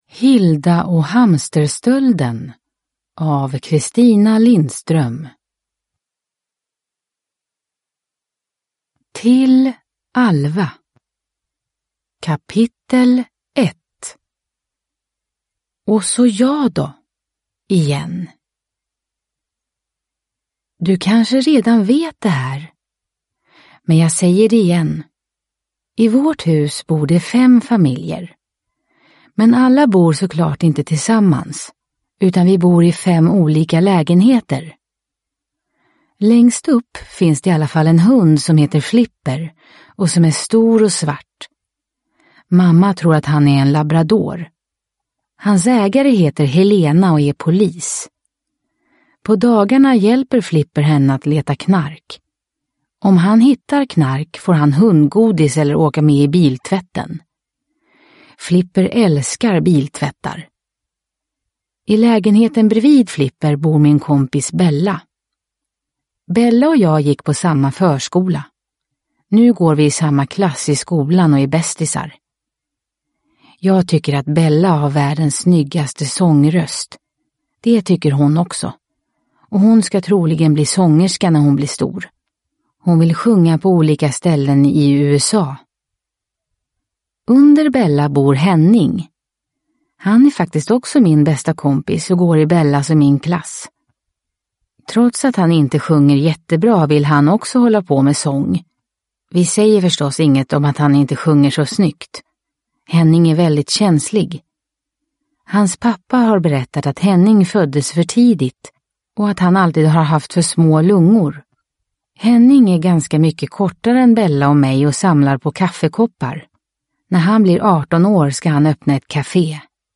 Hilda och hamsterstölden (ljudbok) av Christina Lindström